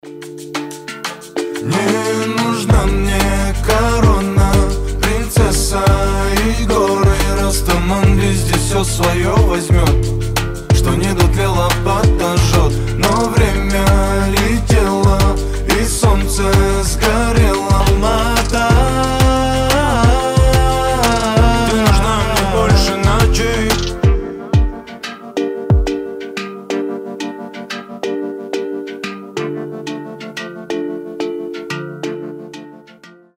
• Качество: 320, Stereo
Хип-хоп
мелодичные